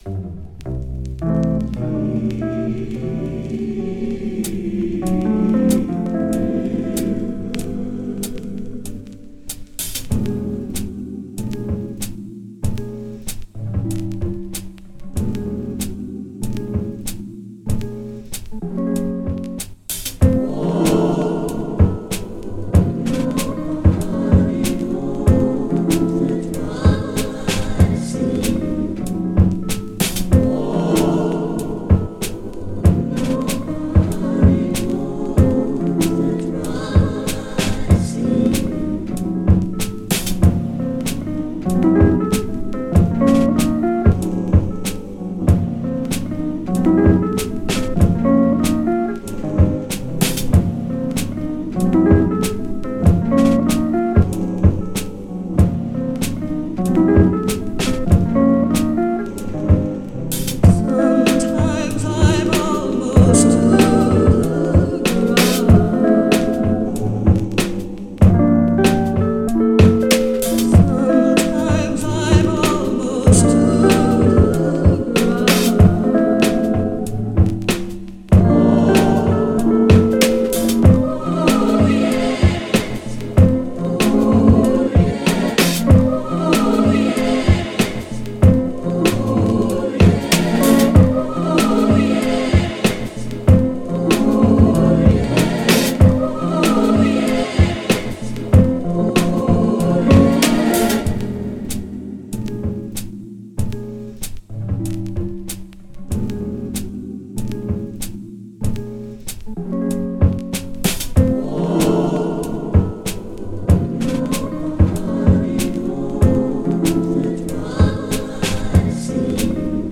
Home > Music > Jazz > Laid Back > Floating > Beats